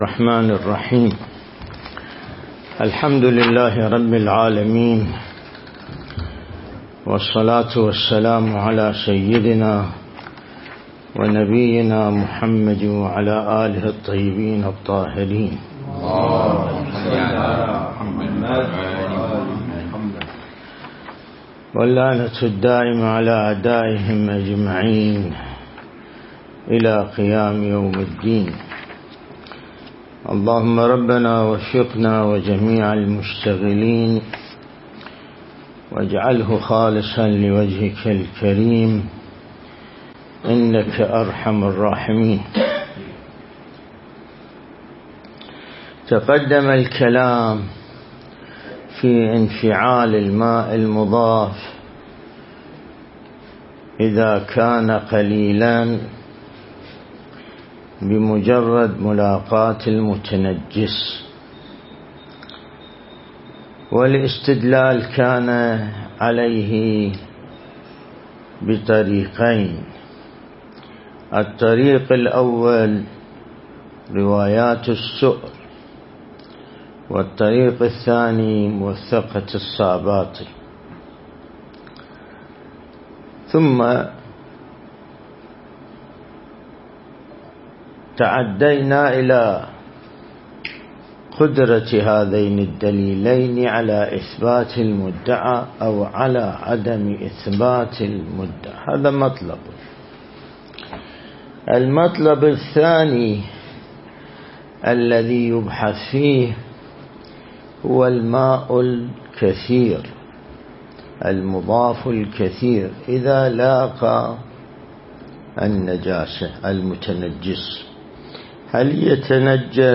درس (91) | الدرس الاستدلالي شرح بحث الطهارة من كتاب العروة الوثقى